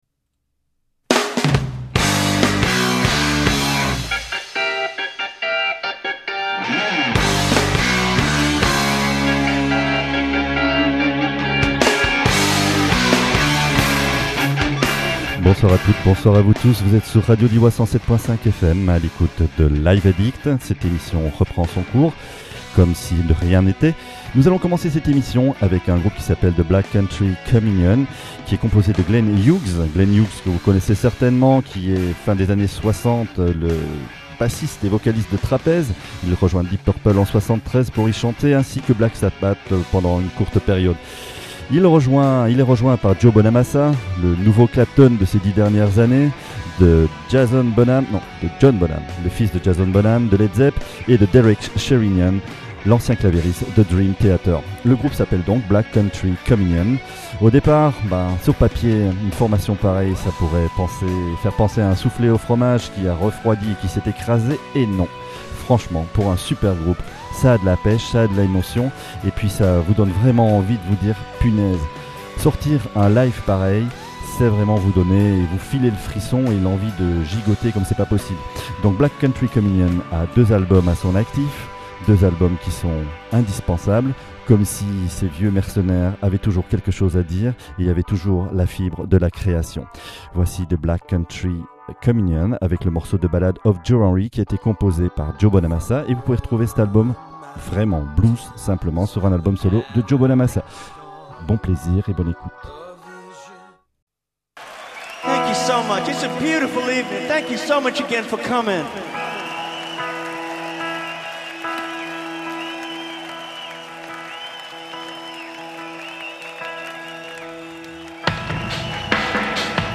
rock , rock progressif